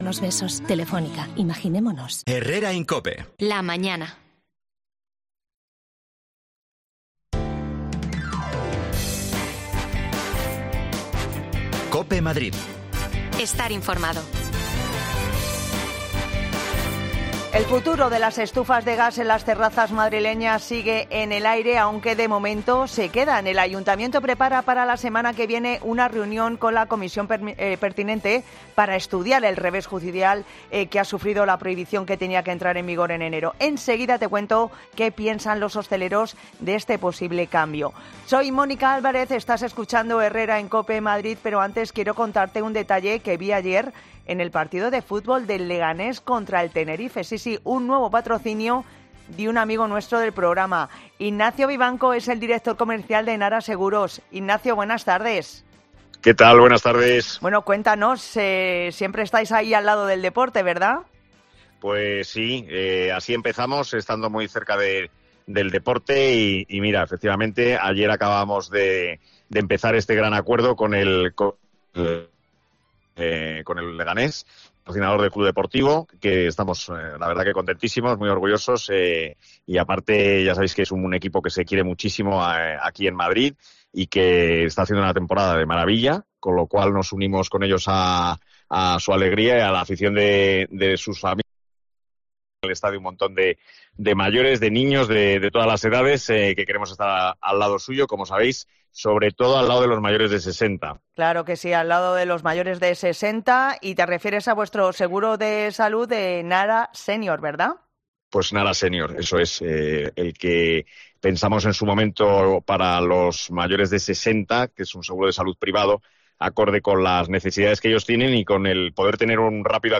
Hablamos con hosteleros tras las sentencia del tribunal de Madrid que anula...